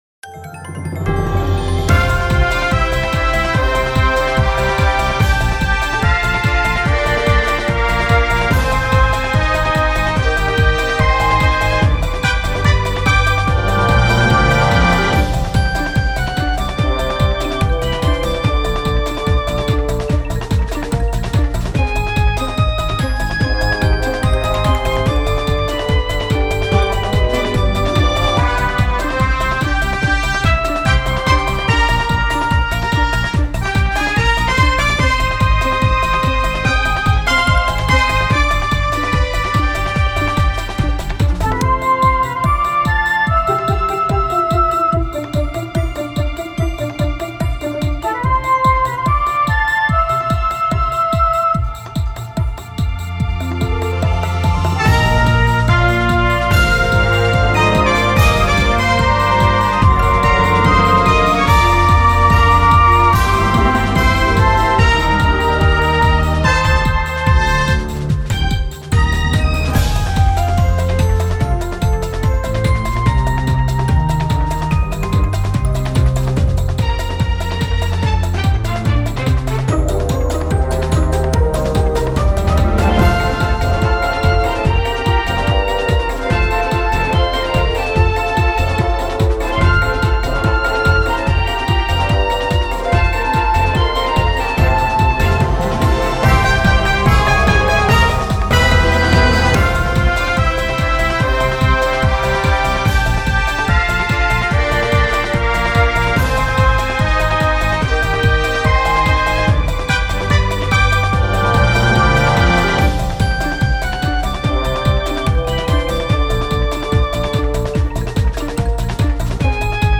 四つ打ちのキックが強調されて、やたらノリノリに聞こえます。